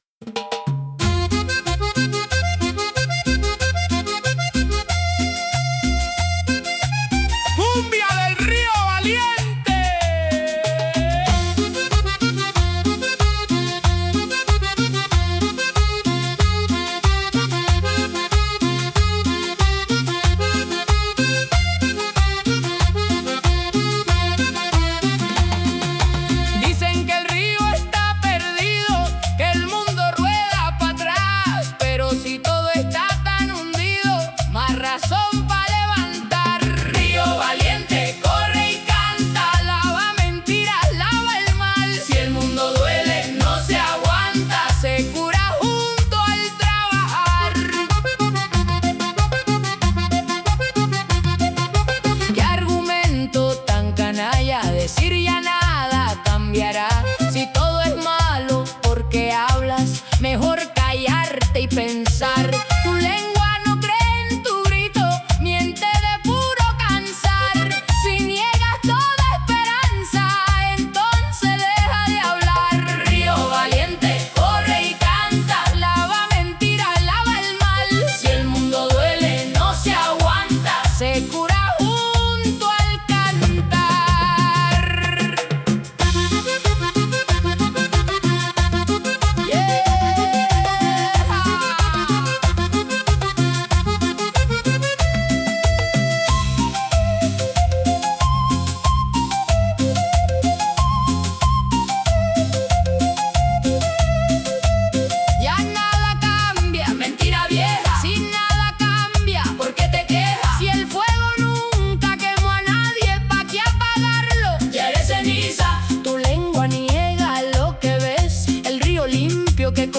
Cumbia tropical andina